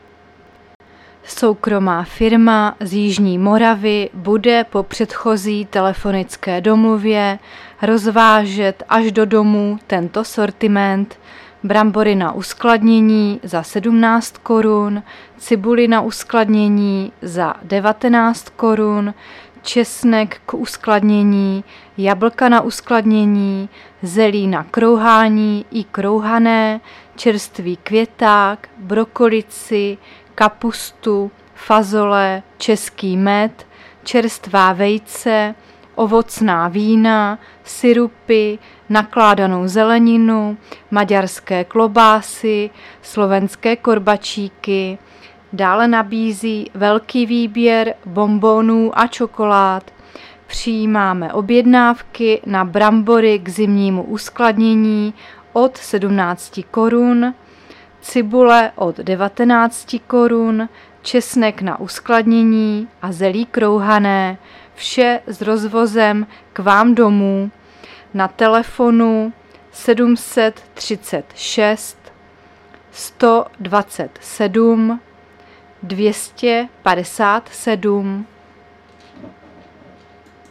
Zařazení: Rozhlas
Záznam hlášení místního rozhlasu 23.10.2023 1.23 MB Stáhnout